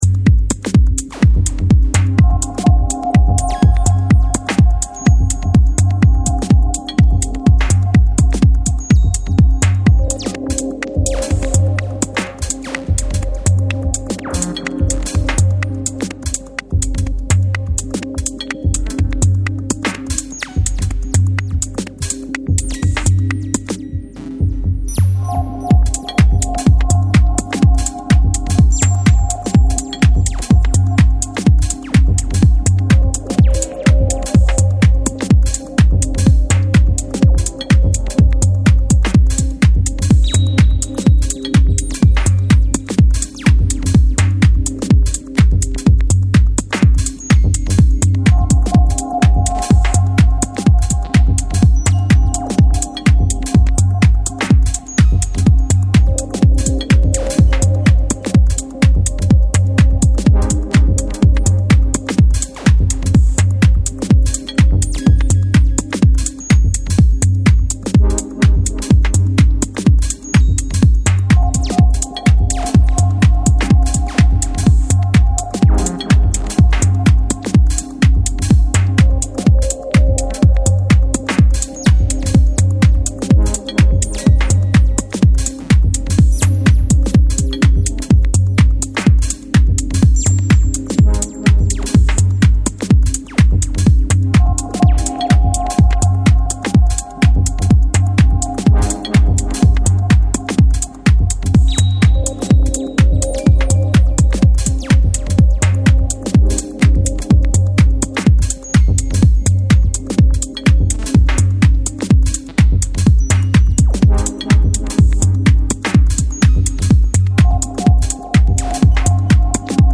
stripped-down and atmospherics tracks
house duo